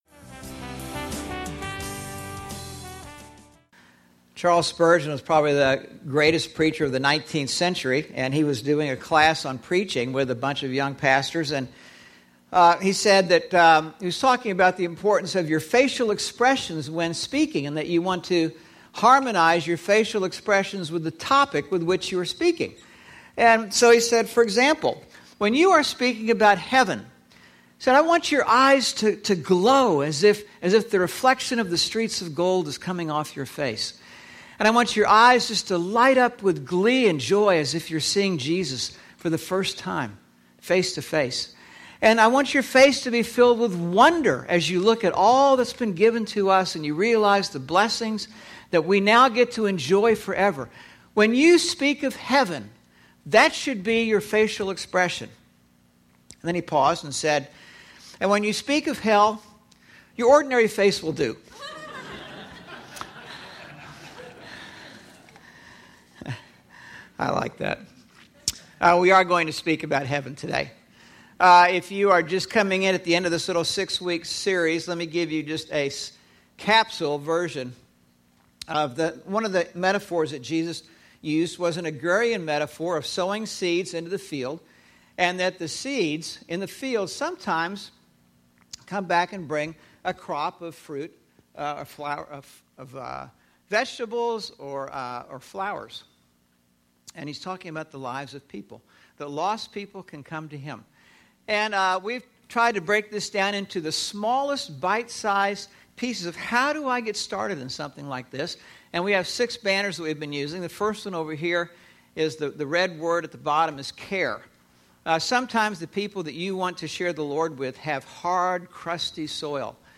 Sermon: Sowing Seeds, Growing Gardens; Joy – Churches in Irvine, CA – Pacific Church of Irvine